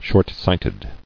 [short·sight·ed]